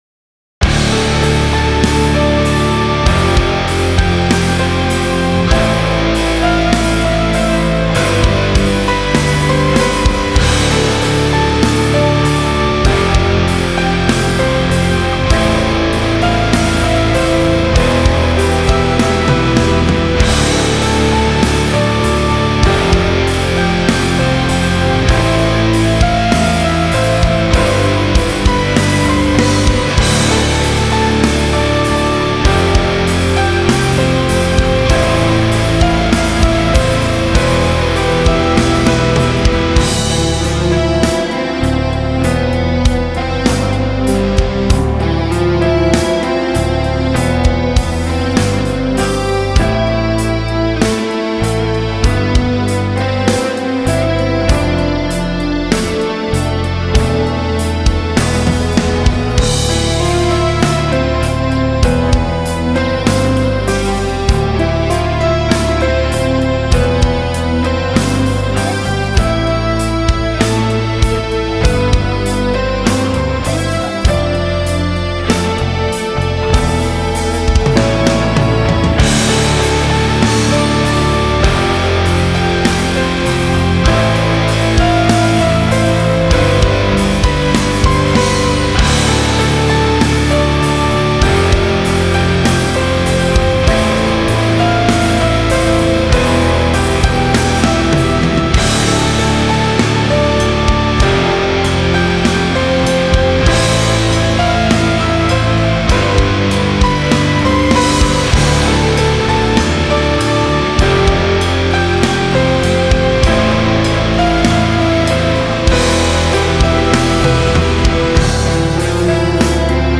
Атмосферик, шугейз, хз...) Темы из прошлого, сочинены в конце 90х, в начале 2000х.